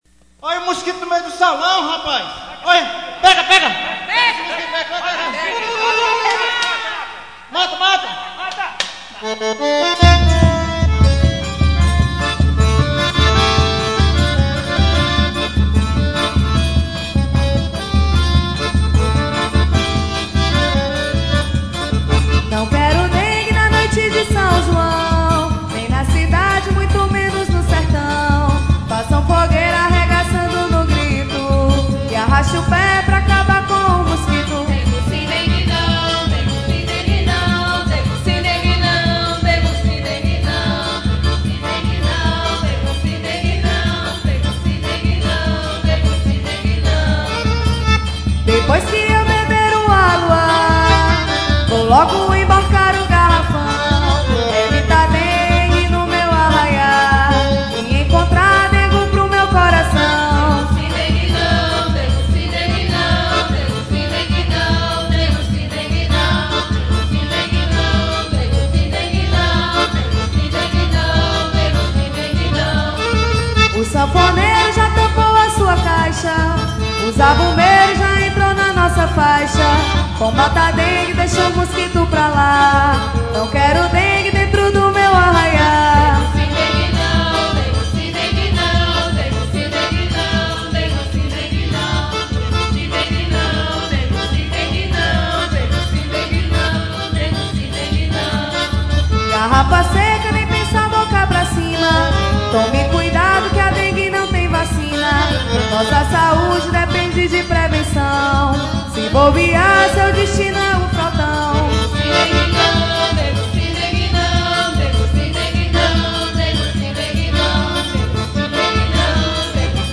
Festa São João da Sociedade de Assistência aos Cegos